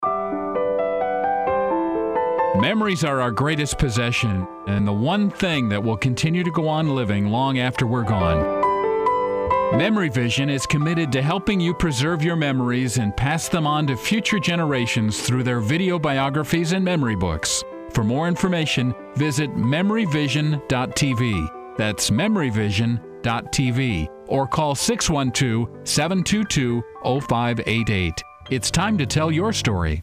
Relevant Radio As heard on Relevant Radio, 1330 AM
RelevantRadioSpot30.mp3